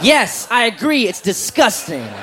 Category: Comedians   Right: Both Personal and Commercial
Tags: aziz ansari aziz ansari comedian